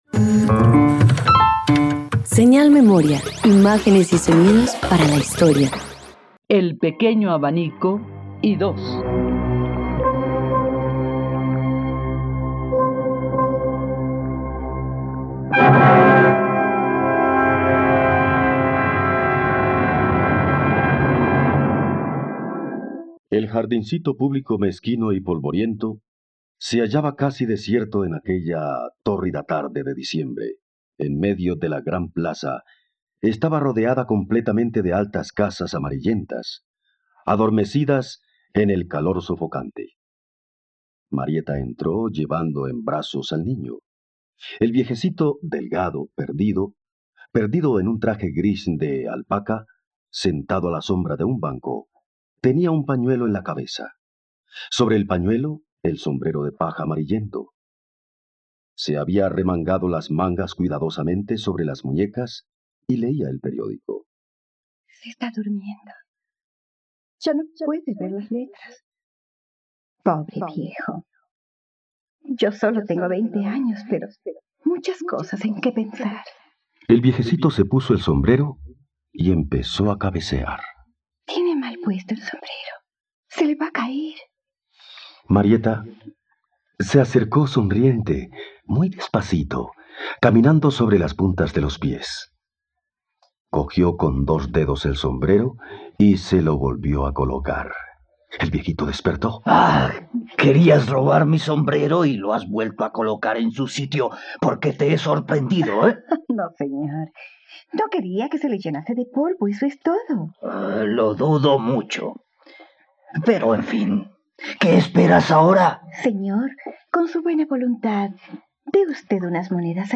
El pequeño abanico: radioteatro dominical